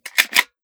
Foley
12ga Pump Shotgun - Load Shells 003.wav